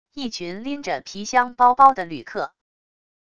一群拎着皮箱包包的旅客wav音频